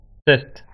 Tonal   speech tonal
Speech_ton.wav